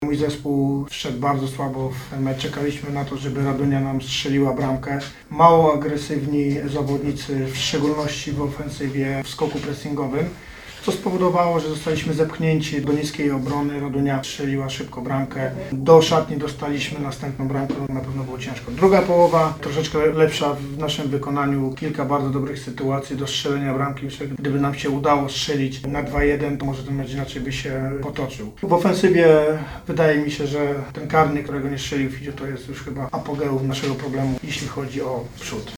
Krytyczny wobec postawy zespołu na pomeczowej konferencji prasowej był trener lubelskich piłkarzy Marek Saganowski: – Mój zespół wszedł bardzo słabo w te mecz.